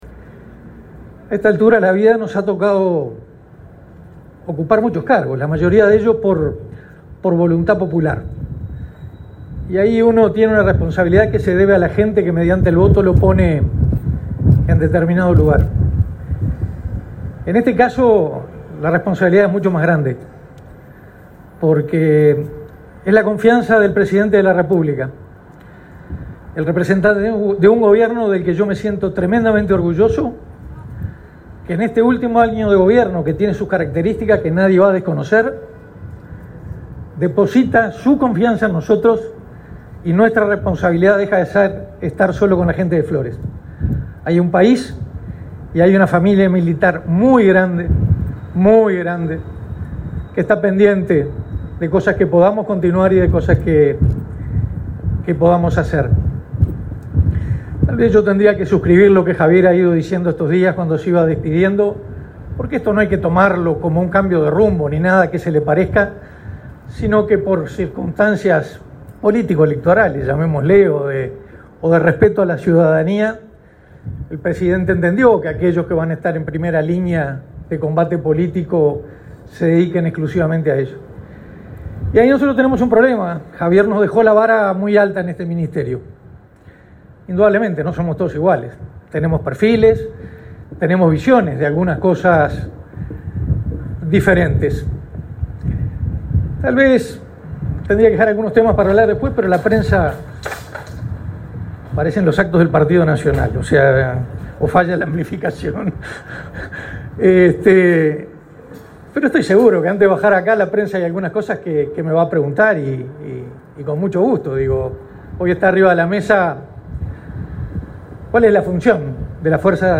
Palabras del nuevo ministro de Defensa Nacional, Armando Castaingdebat
Este lunes 4, en el Ministerio de Defensa Nacional, se realizó el acto de asunción del nuevo secretario de Estado, Armando Castaingdebat.